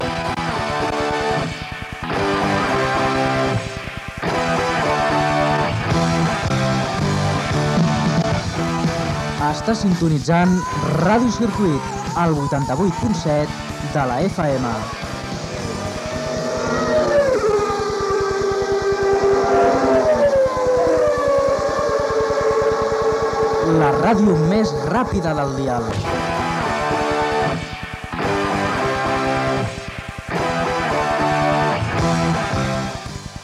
9e51161ecd7012dafa2c2e504892e453c3d7a754.mp3 Títol Ràdio Circuit Emissora Ràdio Circuit Titularitat Tercer sector Tercer sector Altres Descripció Identificació de l'emissora del Circuit de Catalunya.